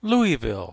Pronunciation
2. /ˈlivɪl/
LOO-ee-vil, and